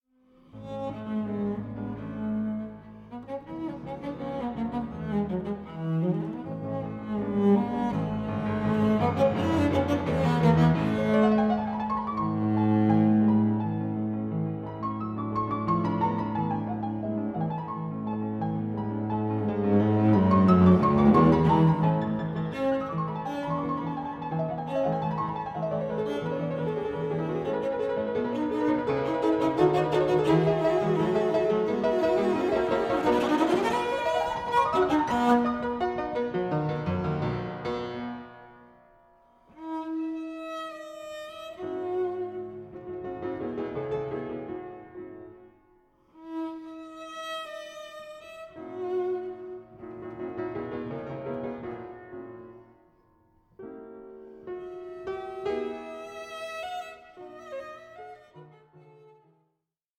Sonata for Pianoforte and Violoncello in A Major, Op. 69